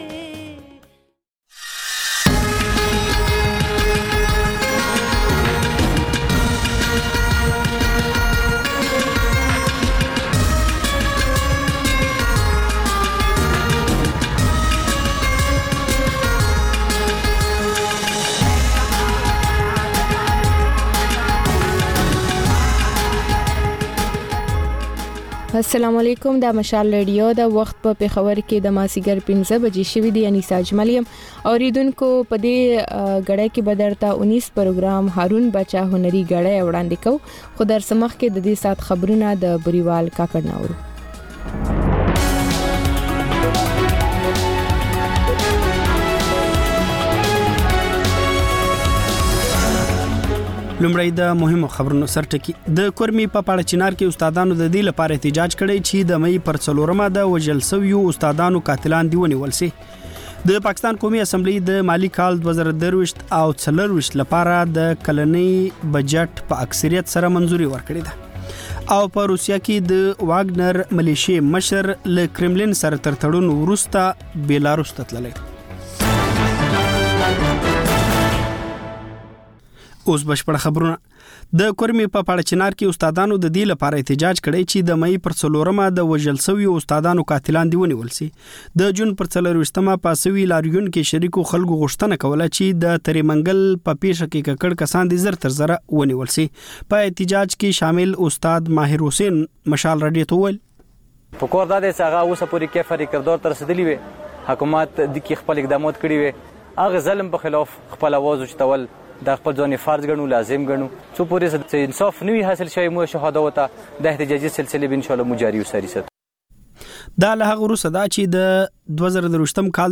د مشال راډیو ماښامنۍ خپرونه. د خپرونې پیل له خبرونو کېږي، بیا ورپسې رپورټونه خپرېږي.
ځېنې ورځې دا ماښامنۍ خپرونه مو یوې ژوندۍ اوونیزې خپرونې ته ځانګړې کړې وي چې تر خبرونو سمدستي وروسته خپرېږي.